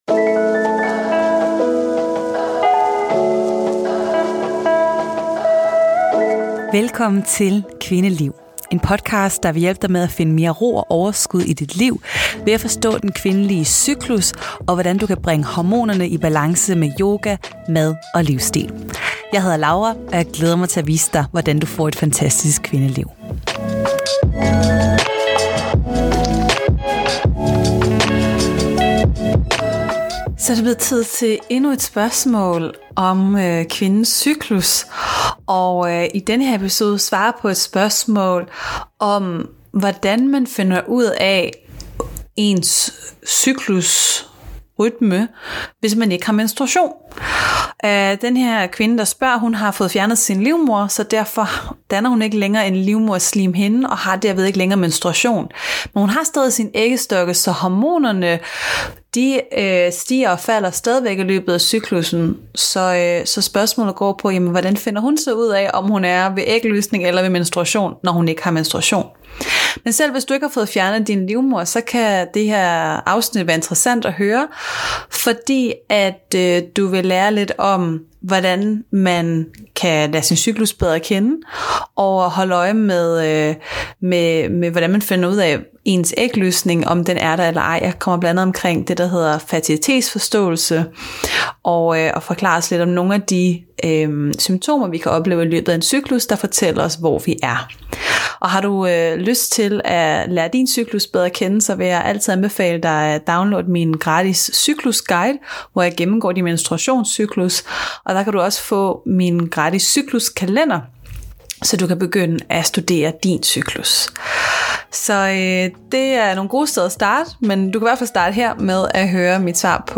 Det spørgsmål svarer jeg på i den her episode, som er en live optagelse fra en af mine spørgetimer med mine medlemmer. Jeg fortæller, hvad du kan holde øje med, som fx symptomer på ægløsning og op til menstruationen. Jeg fortæller også om metoden Fertilitetsforståelse, som kan bruges til at bekræfte ægløsning ved hjælp af at måle temperatur og studere udflåd.